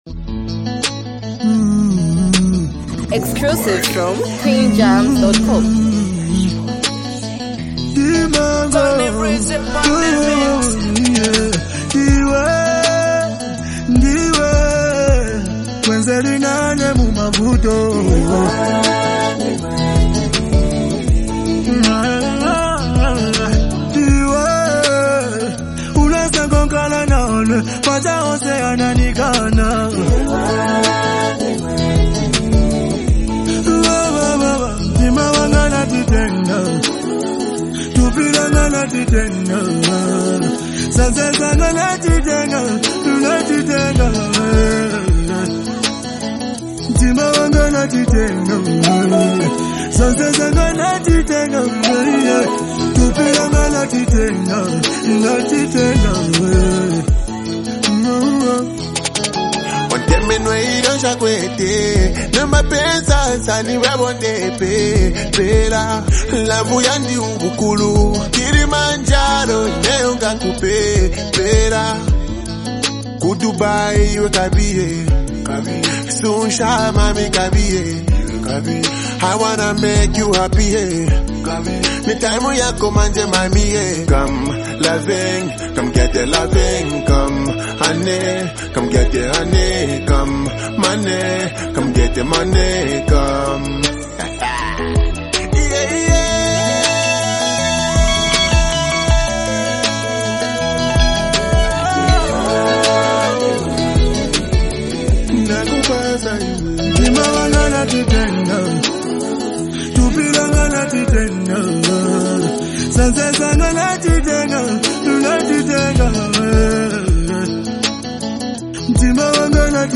vibrant and energetic song
upbeat tempo with rhythmic drums and traditional influences